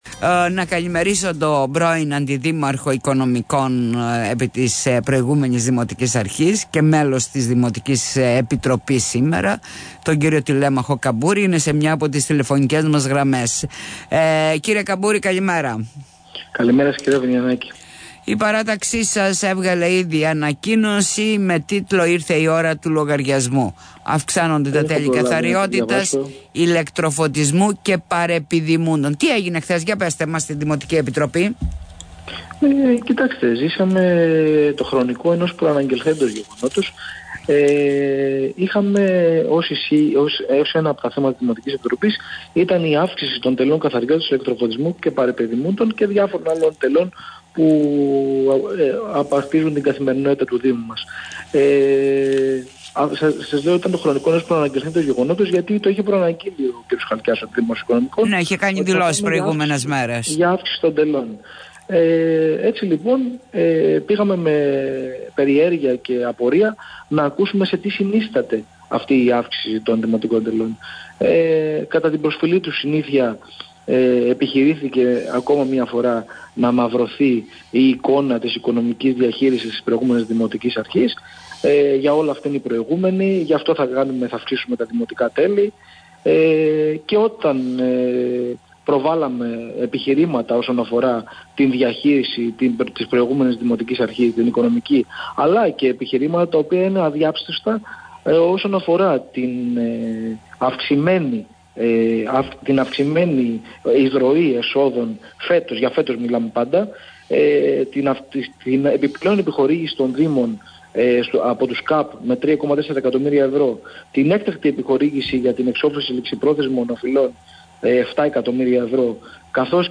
Τηλ. Καμπούρης για συνεδρίαση δημοτικής επιτροπής στον topfm: «Αντί να απαντήσει  ο δήμαρχος με επιχειρήματα για τις αυξήσεις τελών, προχώρησε σε προσωπικές επιθέσεις – Βρίσκεται μακριά από τους πολίτες» (ηχητικό)